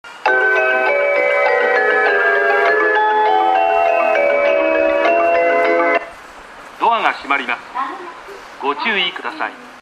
２番線JM：武蔵野線
曲が長いので３番線よりも鳴りにくい傾向があります。